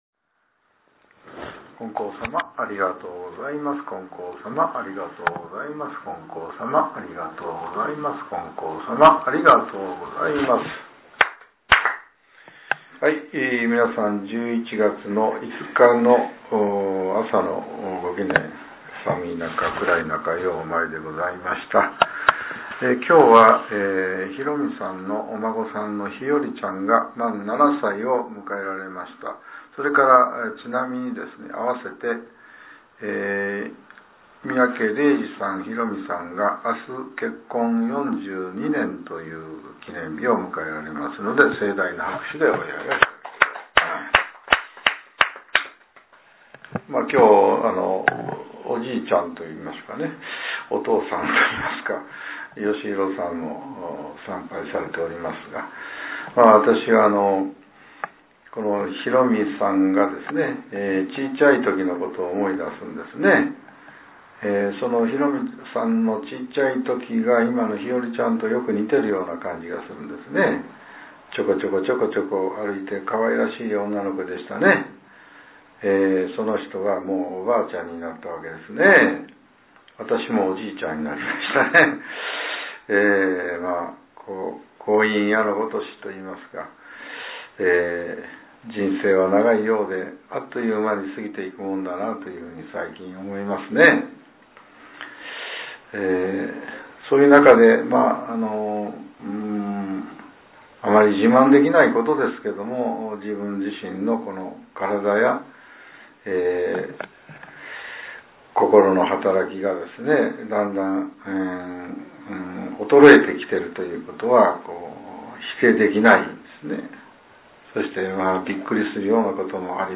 令和７年１１月５日（朝）のお話が、音声ブログとして更新させれています。